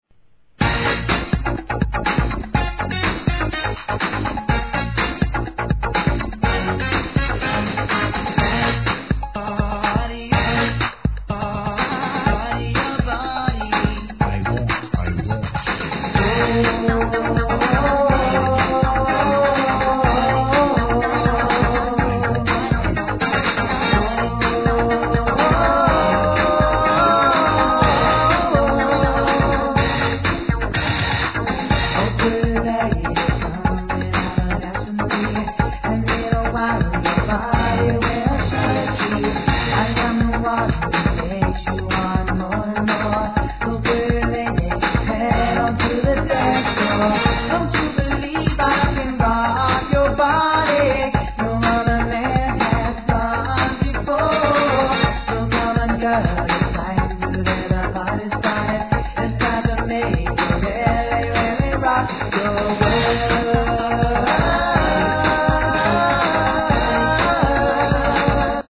HIP HOP/R&B
古めかしいエレクトロなサウンドながら、自然と踊れる好メロディーのPARTYナンバー!!